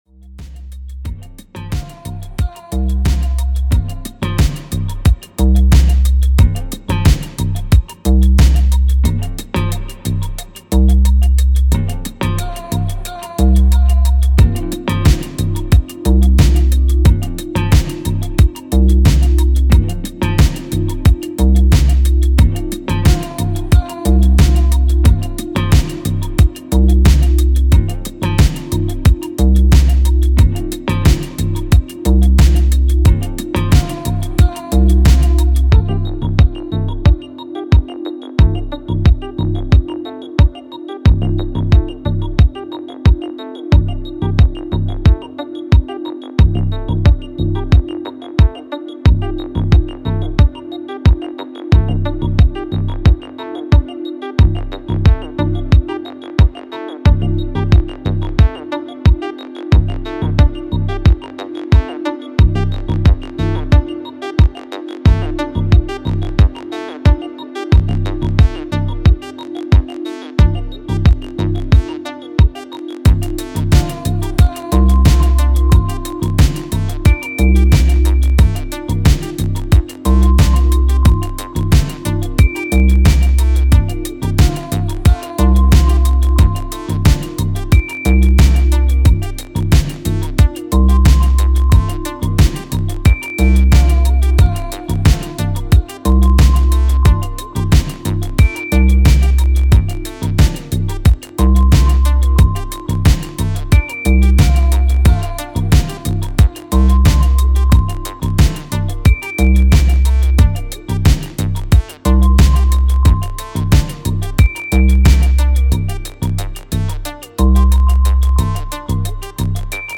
full pelt Dancefloor 4-way